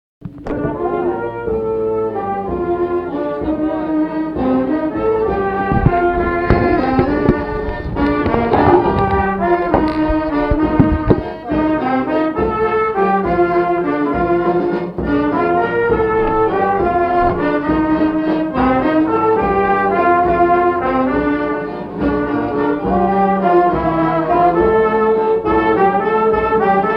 Valse
Mareuil-sur-Lay
danse : valse
Pièce musicale inédite